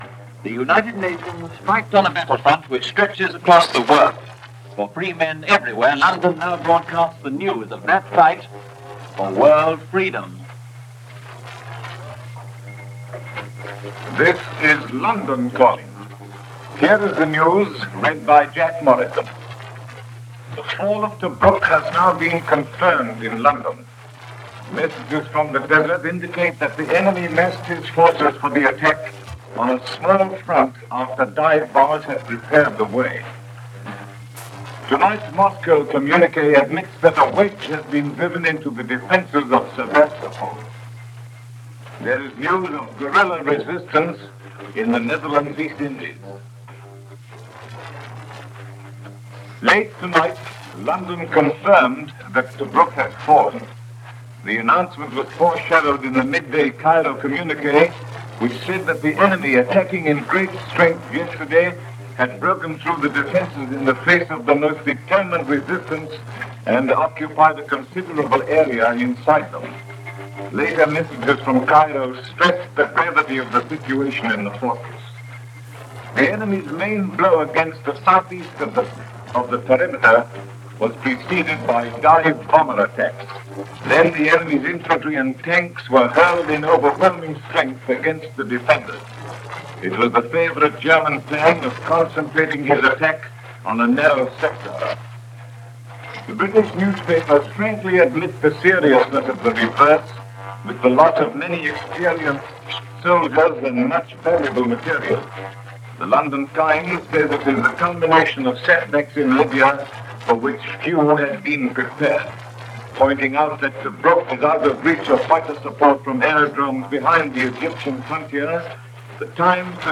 And that’s a small slice of what went on this June 22, 1942 as reported by the BBC World Service as relayed by Radio Australia.